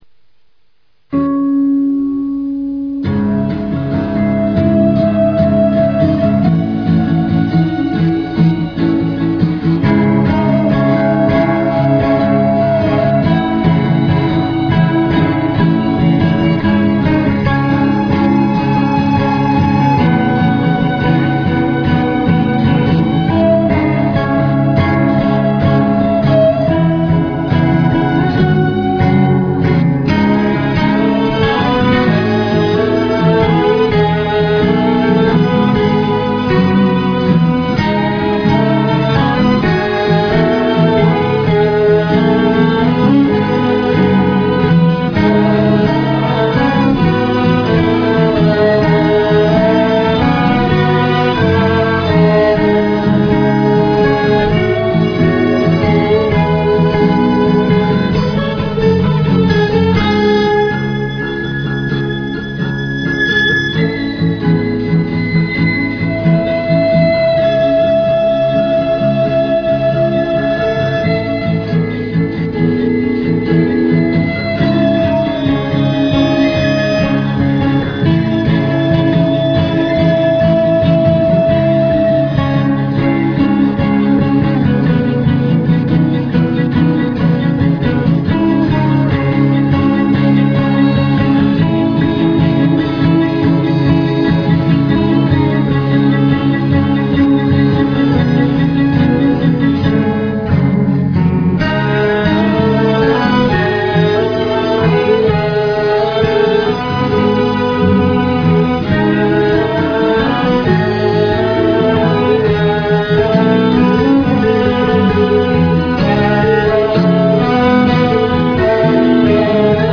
A trashing ballad (maybe not...).